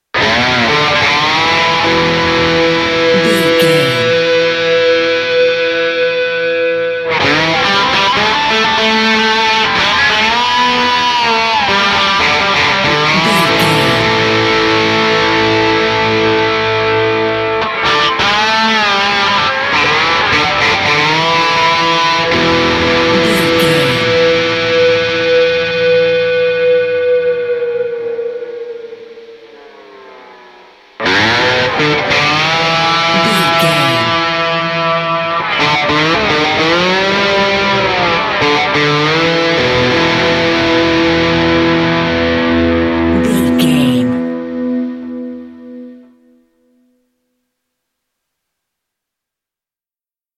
Epic / Action
Ionian/Major
electric guitar
Southern Rock
blues rock
hard rock
driving
lead guitar
Slide Guitar
aggressive
energetic
intense